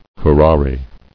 [cu·ra·re]